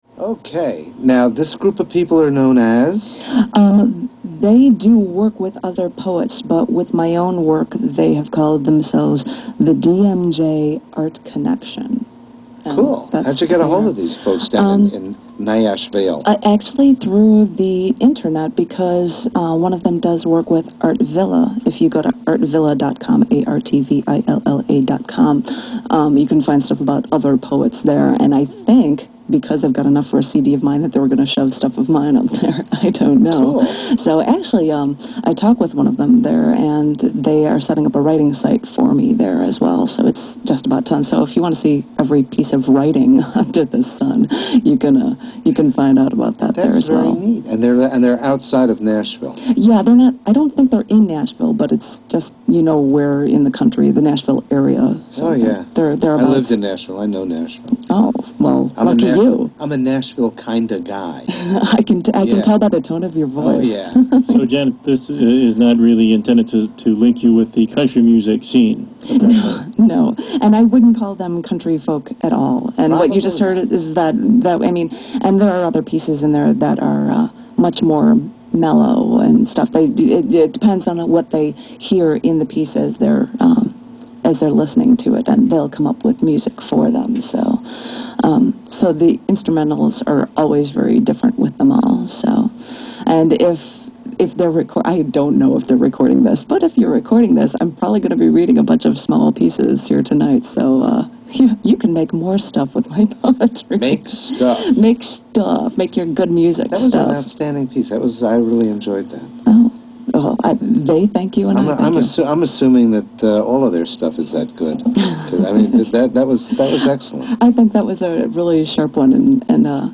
Interview on the Poetry Radio Circus radio show, WZRD 88.3 FM, 11/07/04
Interview